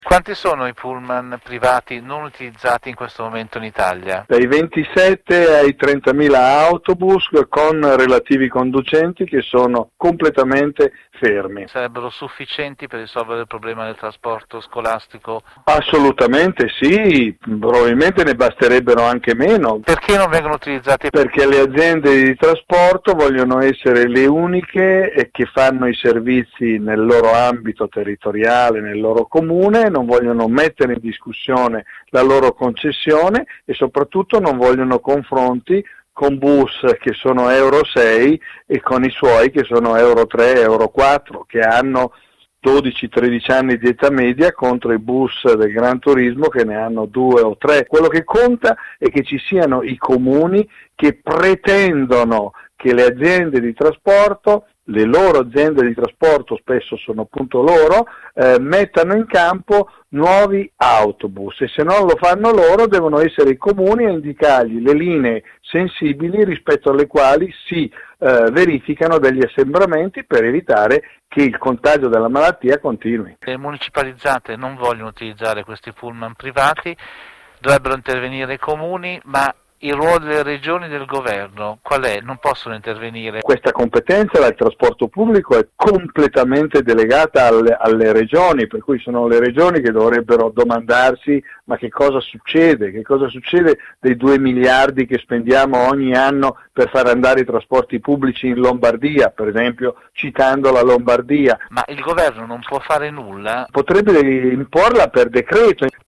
Il racconto della giornata di martedì 5 gennaio 2021 attraverso le notizie principali del giornale radio delle 19.30, dai dati dell’epidemia in Italia alla crisi del governo Conte che non sembra vicina ad una soluzione, mentre i problemi del trasporto pubblico in Italia in vista della riapertura delle scuole sono tutt’altro che risolti.